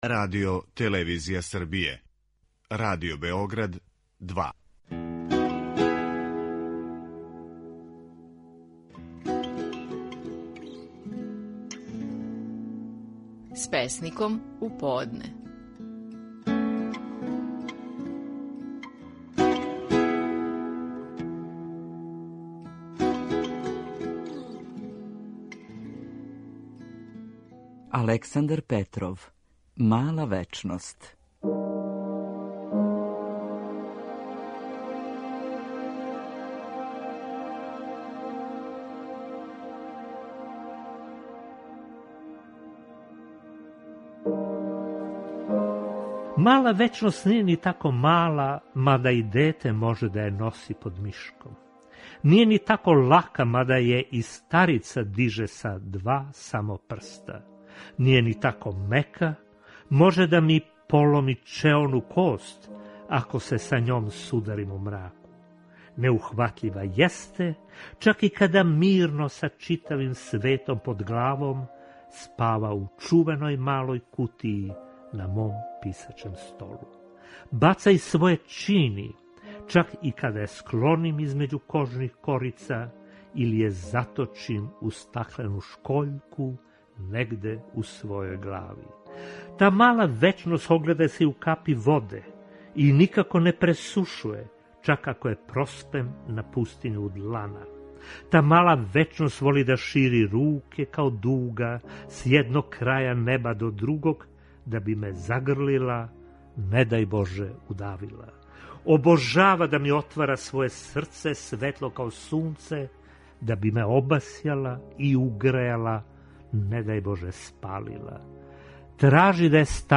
Стихови наших најпознатијих песника, у интерпретацији аутора.
У данашњој емисији, своју песму „Мала вечност" говори песник Александар Петров.